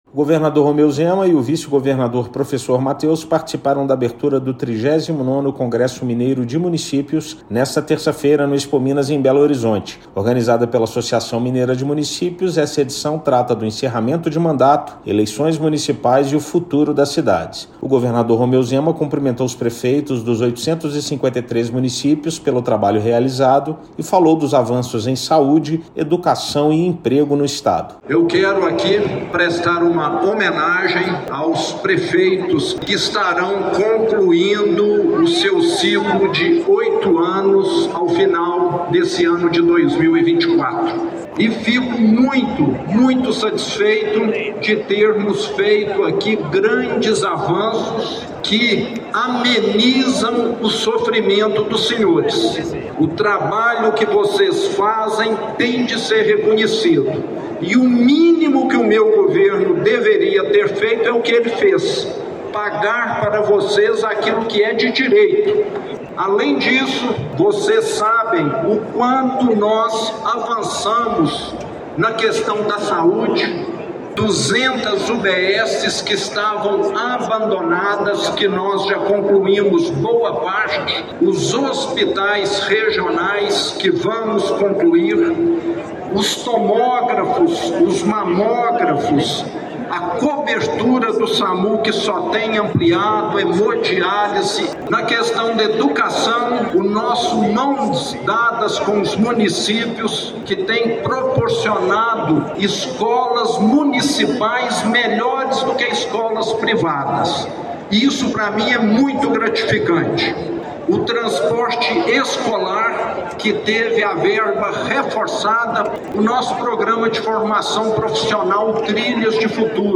Evento promovido pela AMM reúne mais de 10 mil pessoas entre autoridades, agentes municipais e políticos no Expominas, em BH. Ouça matéria de rádio.